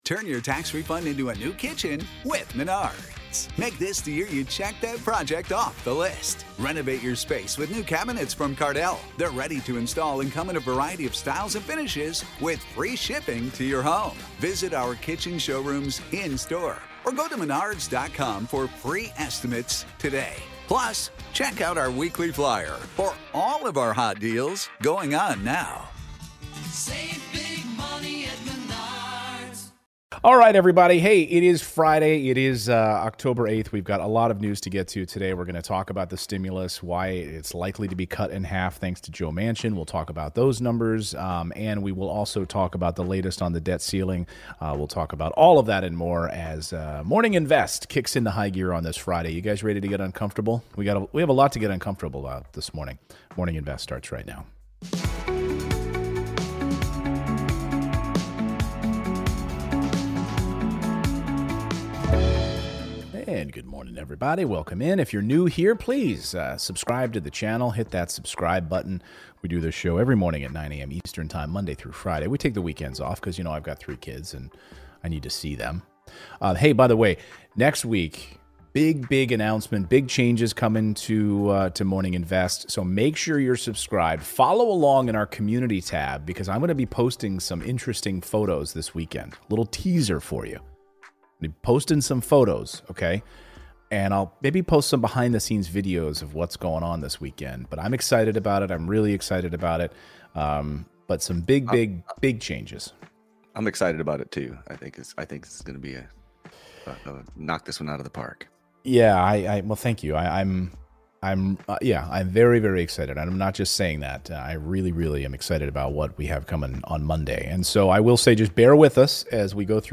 In today's Livestream we're looking at the new bipartisan stimulus package and what President Biden plans to do next. We're also analyzing the jobs data set to be released this morning.